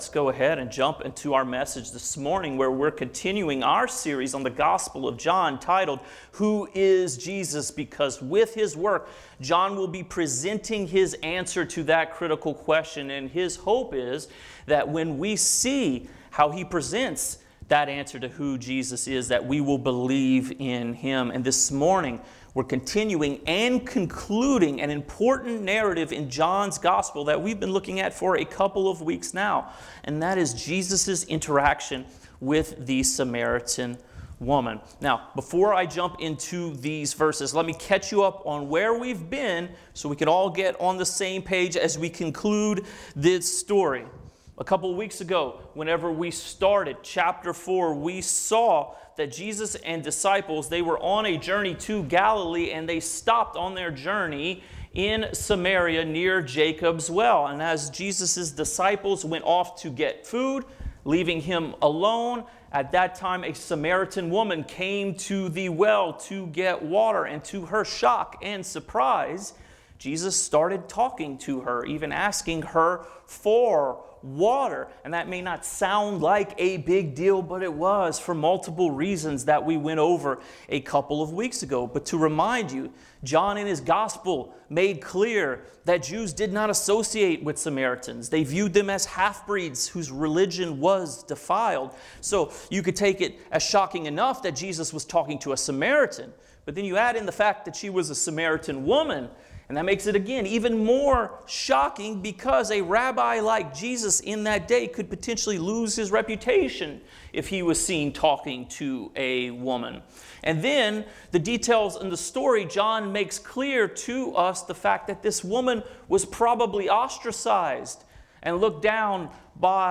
Sermons | Fellowship Baptist Church